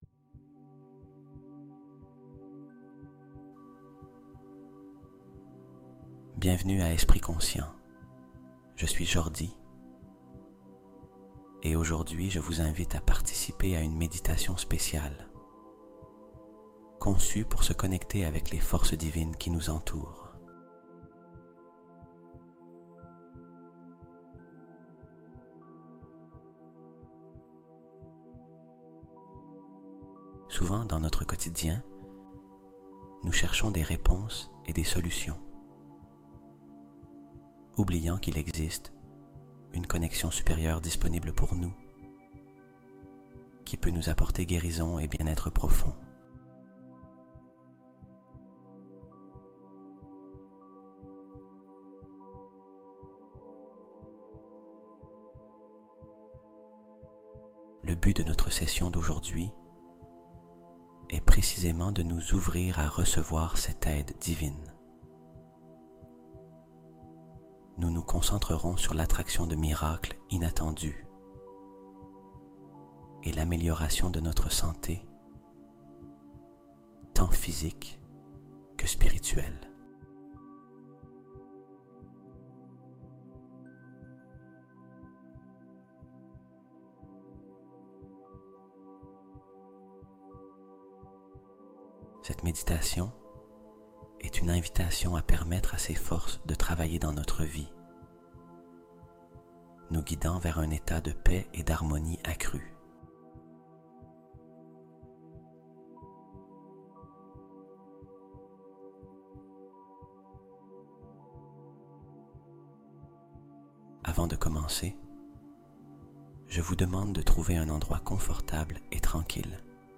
999 Hz FRÉQUENCE D'URGENCE DIVINE | Les Forces Supérieures Interviennent Dans Ta Vie Dans Les 24H